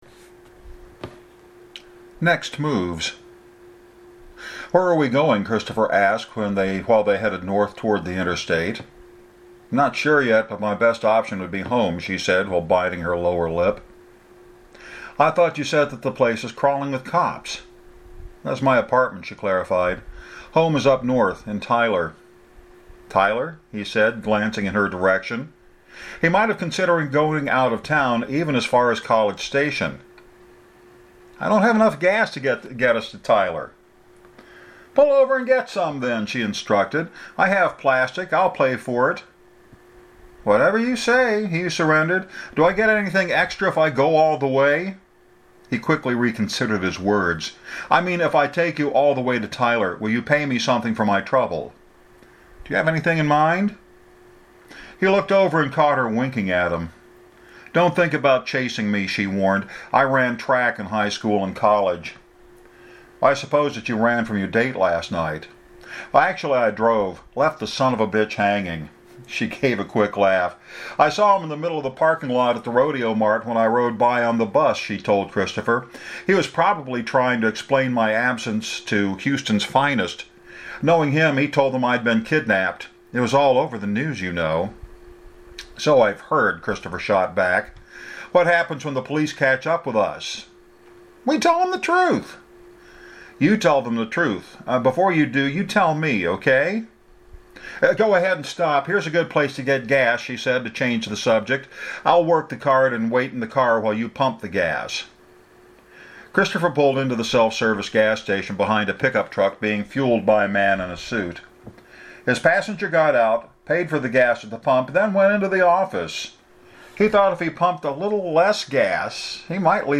A tad rough on the narration this morning, and one or two words were dropped, changing the context.